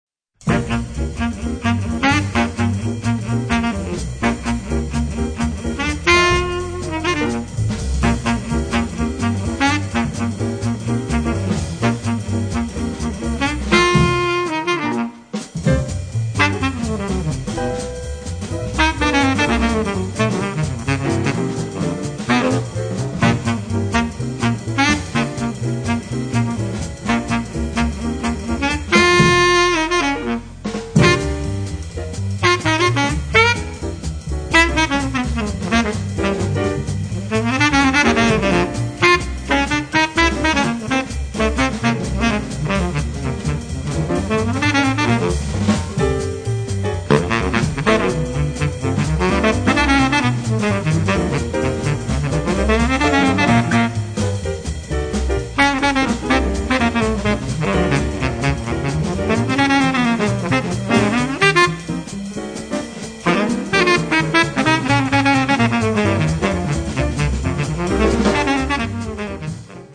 sax baritono, flauto
piano
double bass
batteria